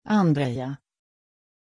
Pronuncia di Andreja
pronunciation-andreja-sv.mp3